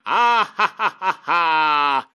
Musiky Bass Free Samples: Voz